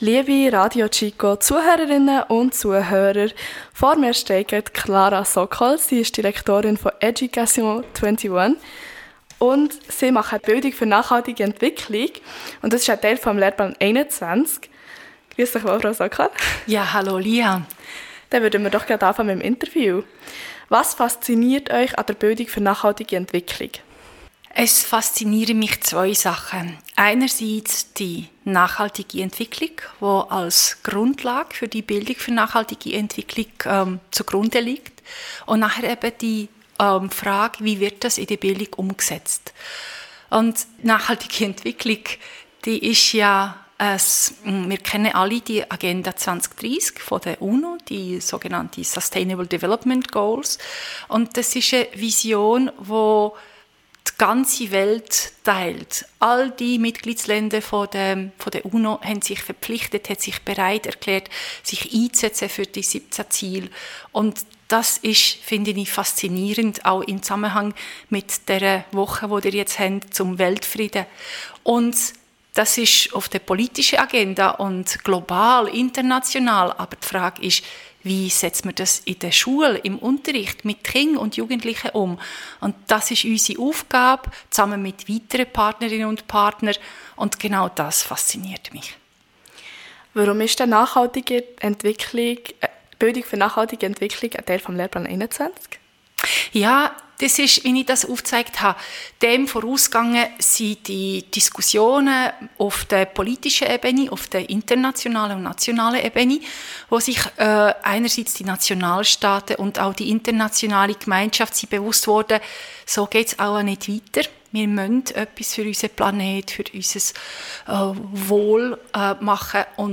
Jugend- und Schulradio